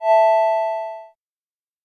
chiptune
重做连击音效